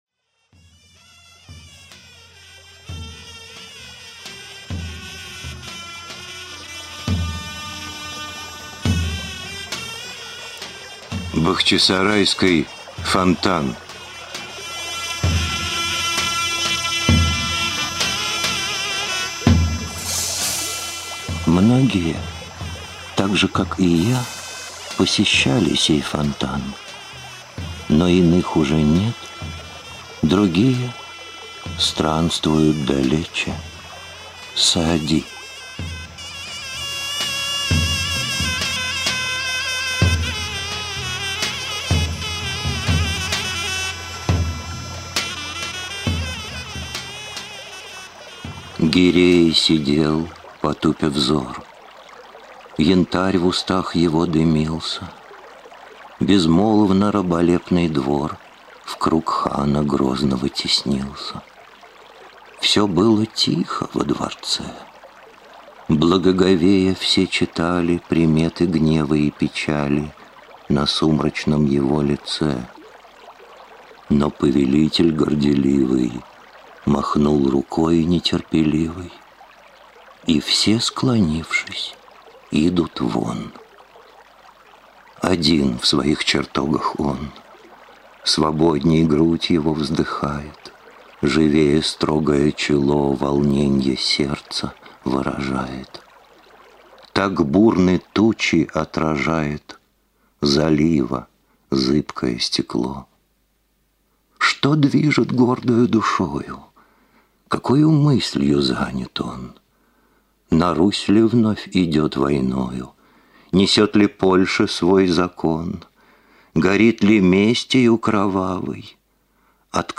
Аудиокнига Бахчисарайский фонтан
Качество озвучивания весьма высокое.